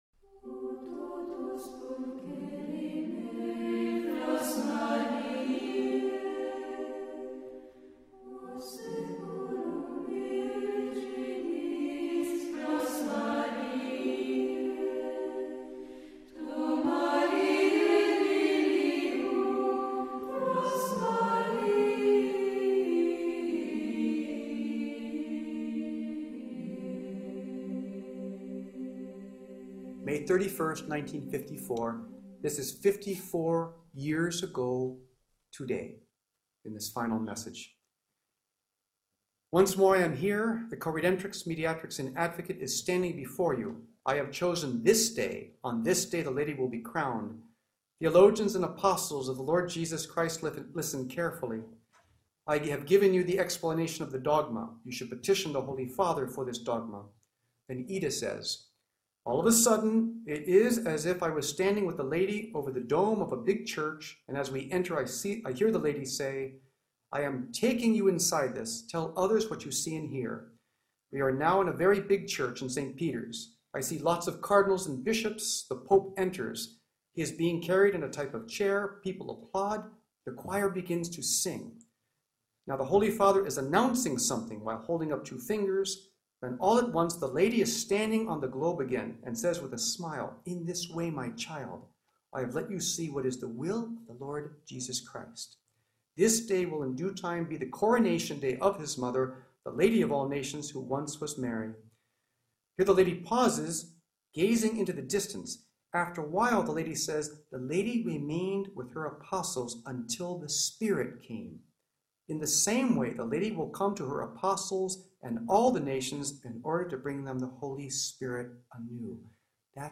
A major Marian conference discussing the impact the proclamation of the Coredemption Dogma would have on the Church for the triumph of the Immaculate Heart of Mary in the context of approved Marian Apparitions. This conference was held on the anniversary of the last apparition of Our Lady of All Nations on May 31, 1959, the feast of the Visitation in Amsterdam, Netherlands where the apparitions took place along side one of the famous Dutch canals at the historic Victoria Hotel in Park Plaza at the heart of the city in the presence of 100 select guests.